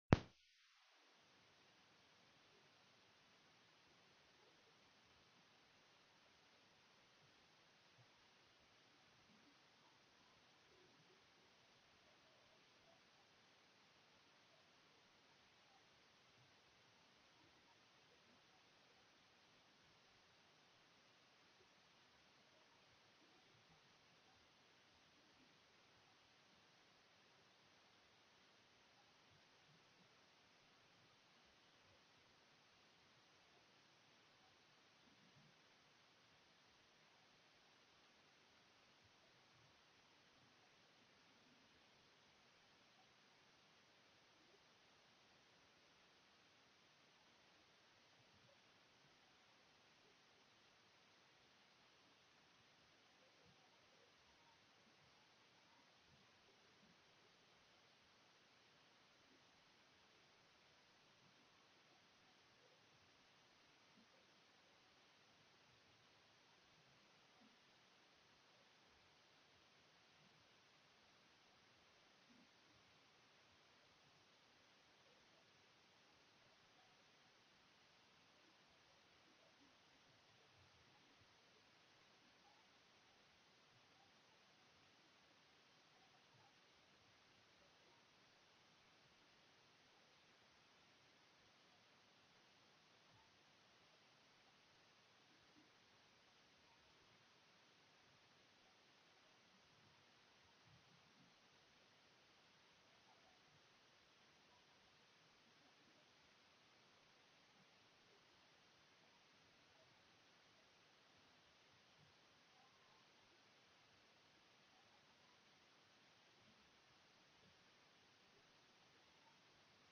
The audio recordings are captured by our records offices as the official record of the meeting and will have more accurate timestamps.
1:03:00 PM Council took a brief at ease due to teleconference audio difficulties.